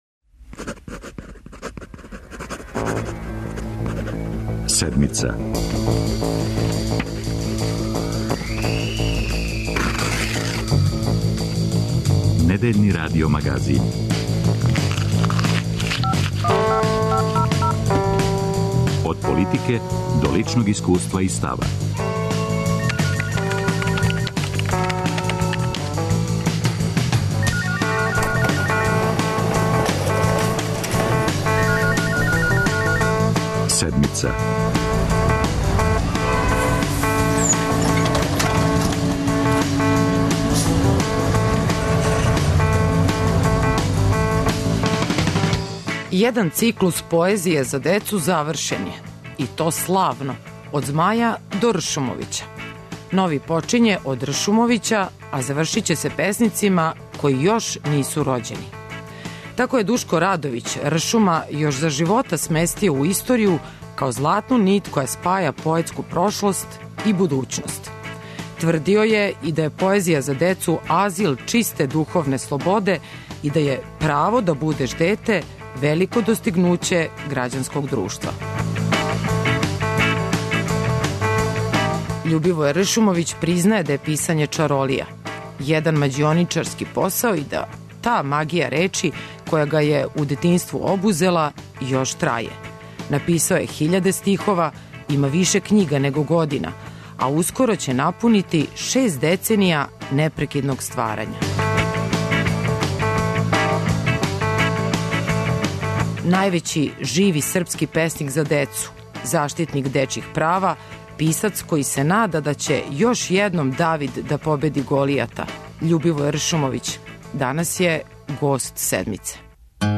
Гост Седмице је Љубивоје Ршумовић.